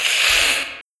neko-angry.wav